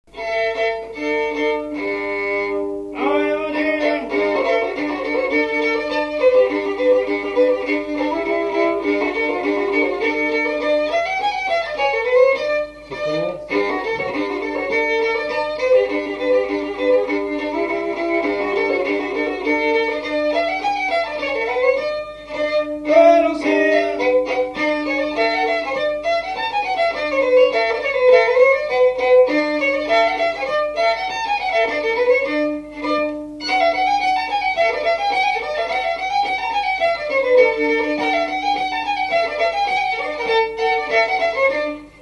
Résumé instrumental
danse : pas d'été
Pièce musicale inédite